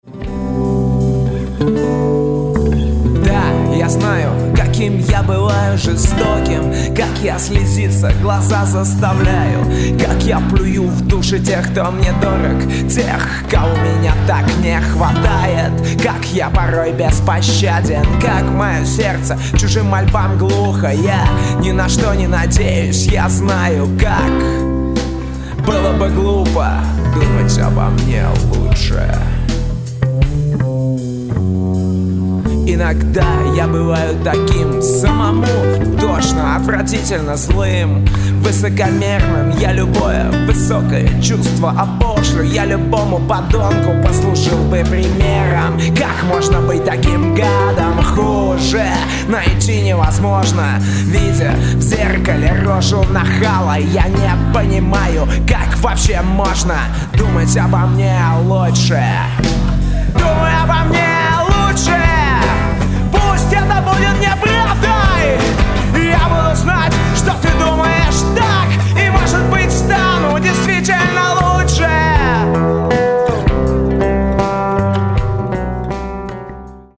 оставив для создания живой атмосферы звук из зала.